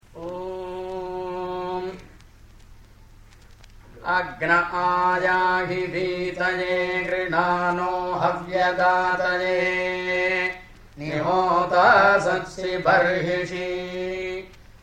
The difference is between between that of chanting and of recitation: priests chant the Samaveda with melodies that can involve a range of more than an octave.
Samhitā